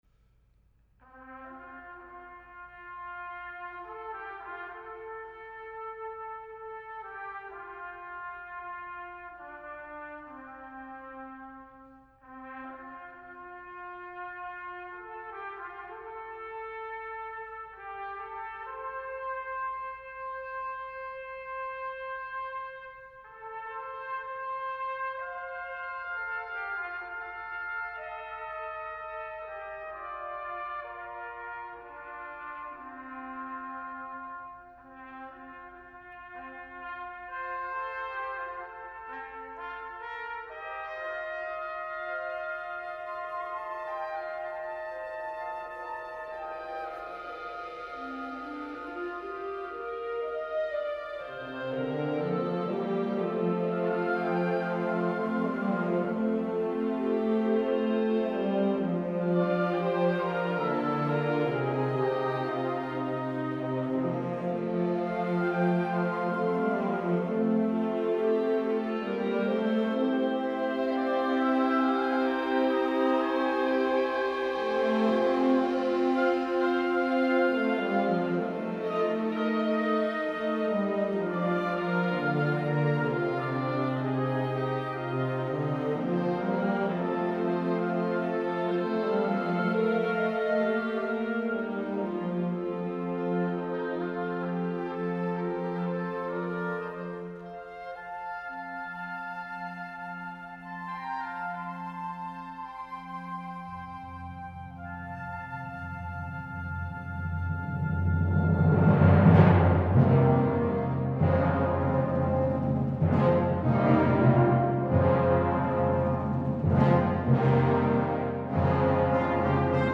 for Band (1985, rev. 1986)